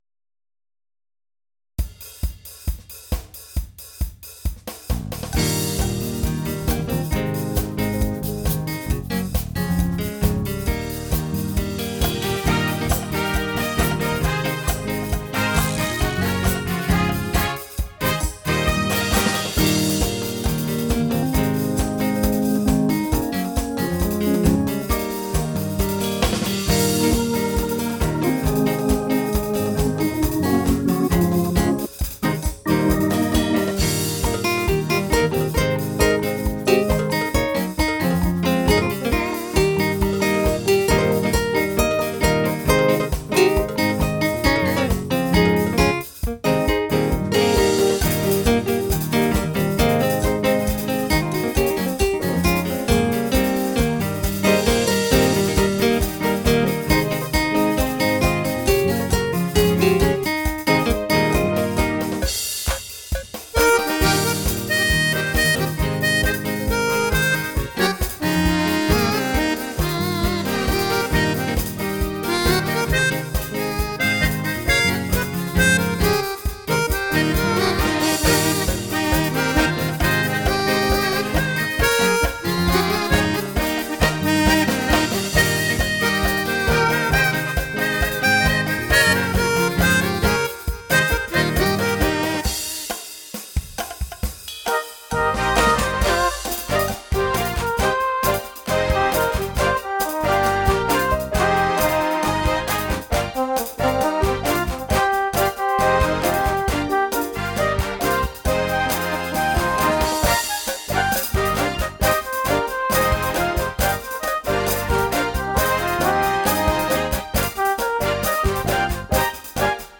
on a Roland FA-06 Music Workstation
Creation and Production done entirely on the workstation.